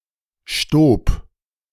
Stoob (German pronunciation: [ʃtoːp]
De-Stoob.ogg.mp3